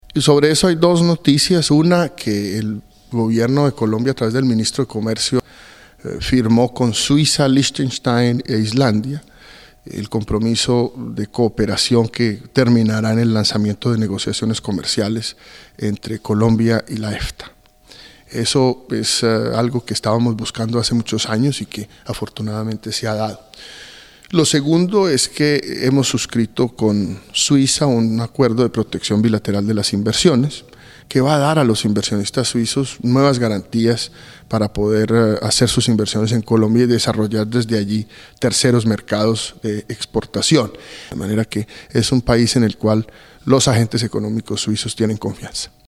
El presidente de la Asociación Nacional de Empresarios de Colombia (ANDI), Luis Carlos Villegas, habla a swissinfo sobre los recientes acuerdos firmados entre su país y Suiza en materia de intercambio económico y comercial.